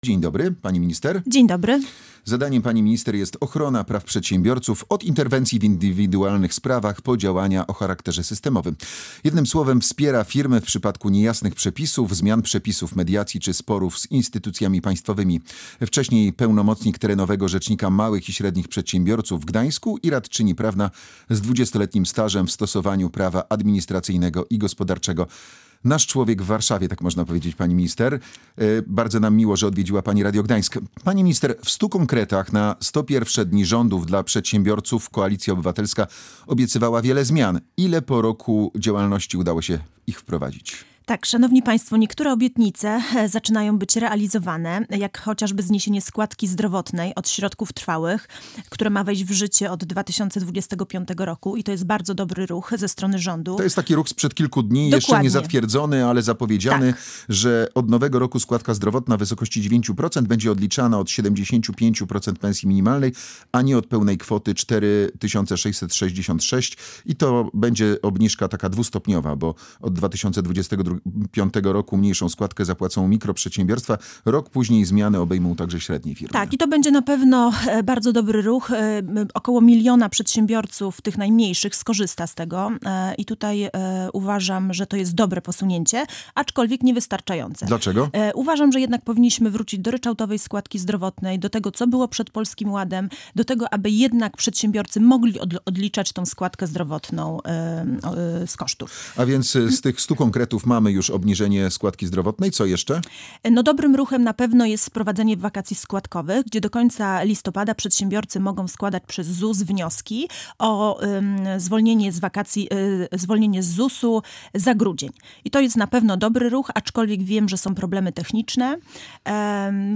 I jest to przykład bardzo dobrego ruchu ze strony rządu – oceniła na antenie Radia Gdańsk minister Agnieszka Majewska, pełniąca funkcję Rzecznika Małych i Średnich Przedsiębiorców.